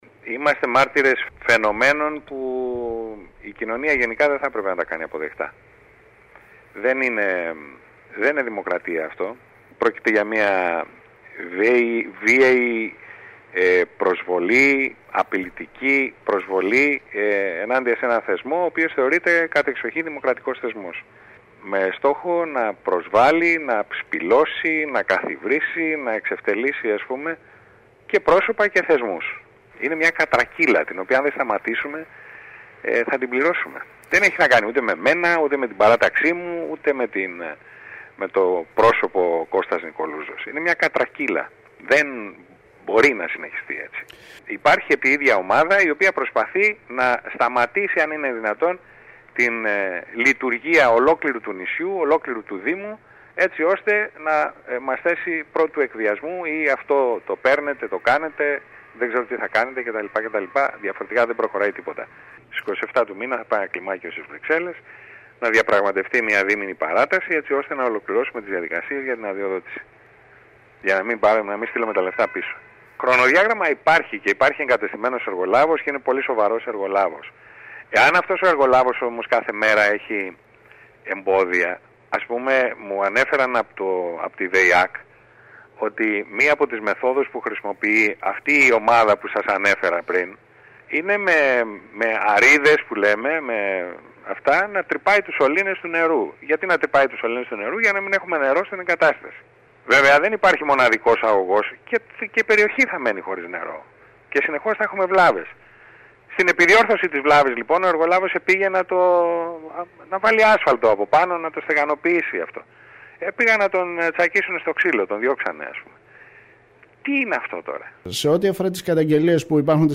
Κάτοικοι της Λευκίμμης που εναντιώνονται στην λειτουργία του ΧΥΤΑ Νότου βρέθηκαν στη χθεσινή συνεδρίαση του Δημοτικού Συμβουλίου Κέρκυρας, εμποδίζοντας  την έναρξή του. Για βάναυση προσβολή της τοπικής αυτοδιοίκησης έκανε λόγο ο Δήμαρχος Κέρκυρας μιλώντας στο σταθμό μας.